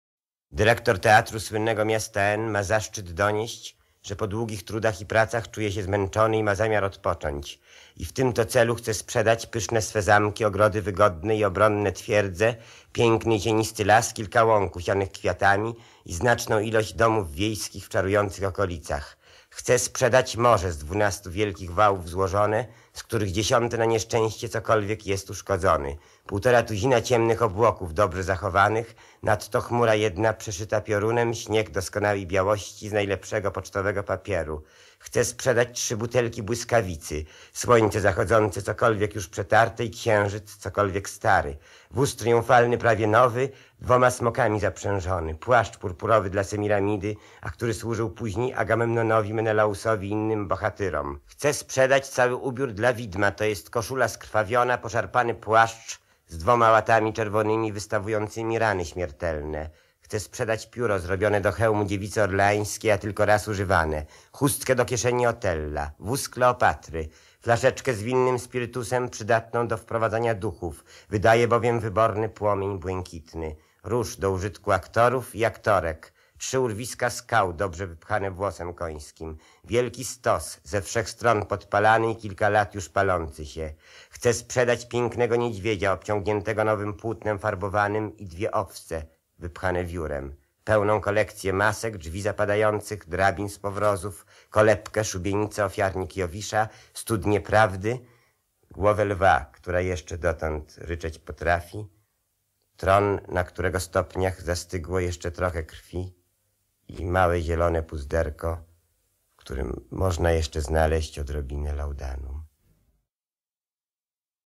Piotr Skrzynecki, w Piwnicy pod Baranami lekko to przerabiając wyrecytował pięknie około roku dwutysięcznego.